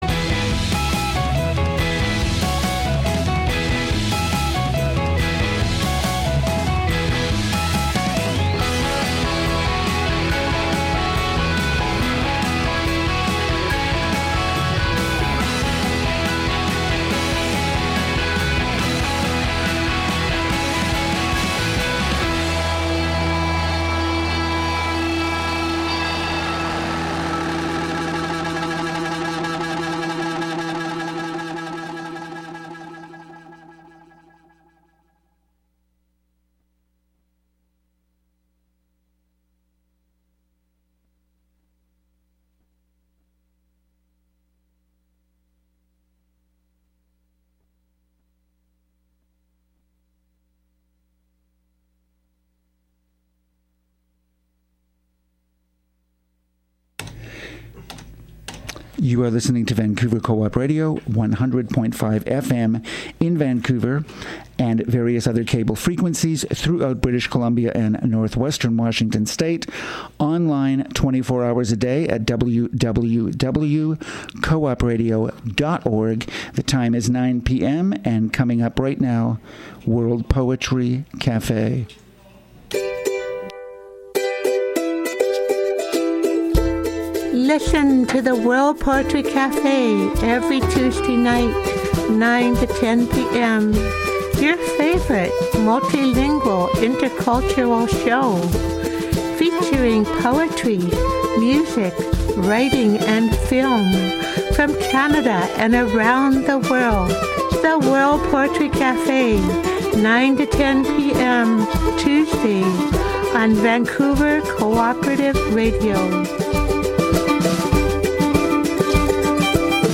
Please be patient, there is about a minute of dead time after the first piece of intro music.